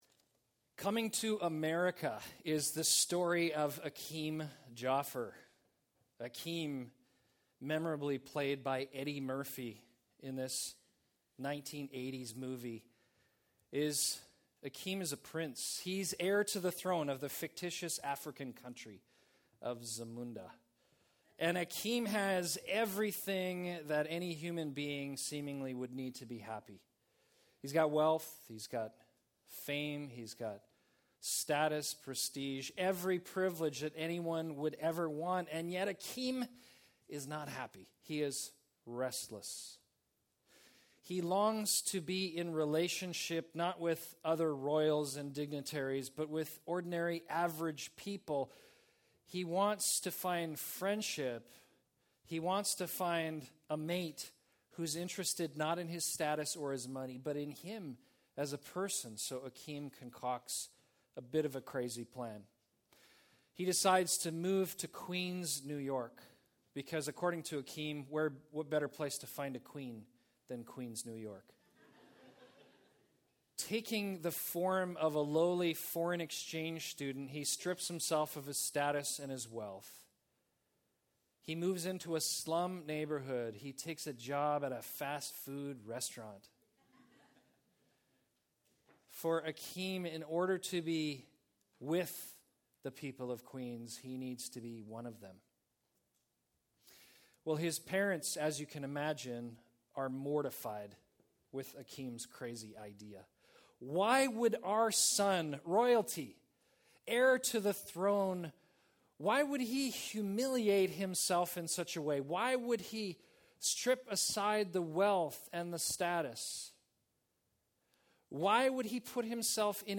Episode from Tenth Church Sermons